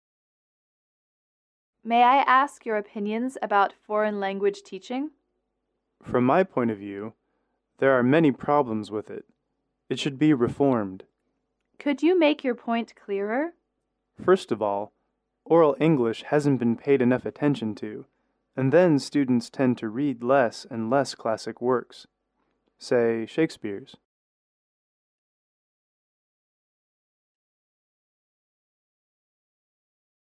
英语口语情景短对话58-4：对外语教学的看法（MP3）